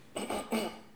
raclement-gorge_01.wav